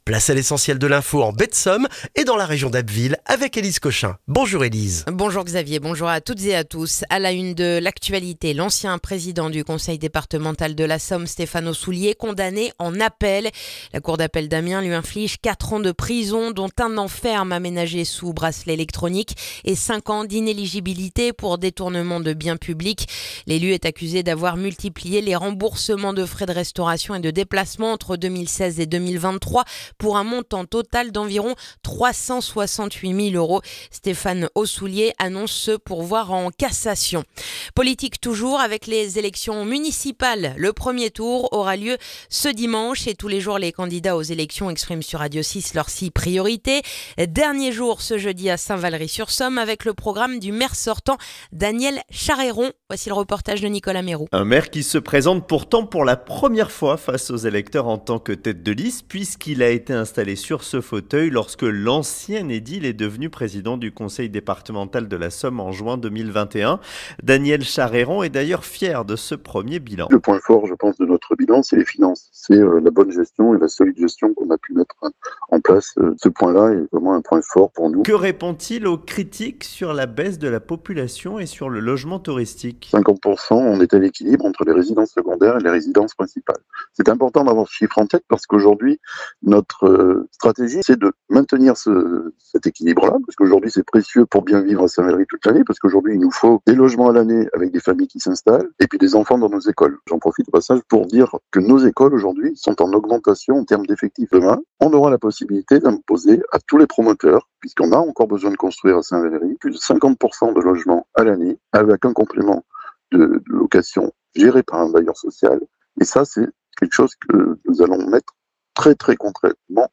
Le journal du jeudi 12 mars en Baie de Somme et dans la région d'Abbeville